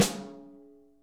Index of /90_sSampleCDs/Best Service - Real Mega Drums VOL-1/Partition D/AMB KIT 02EC